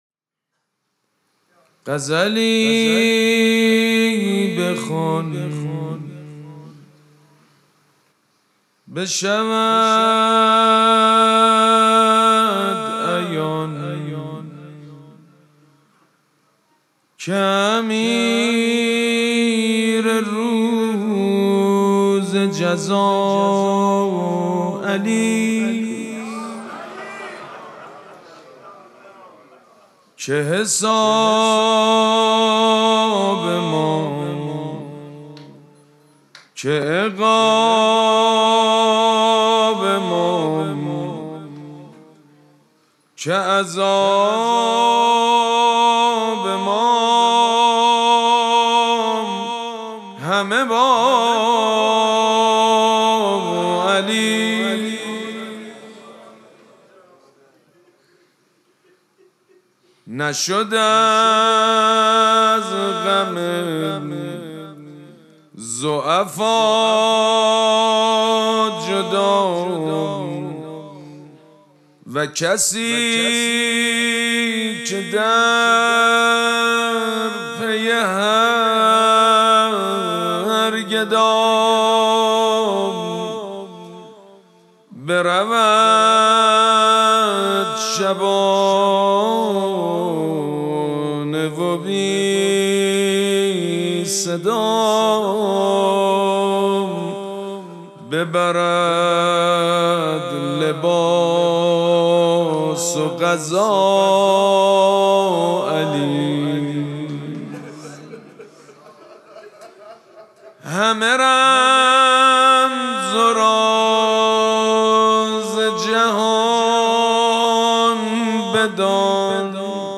مراسم مناجات شب بیست و دوم ماه مبارک رمضان
حسینیه ریحانه الحسین سلام الله علیها
مدح
حاج سید مجید بنی فاطمه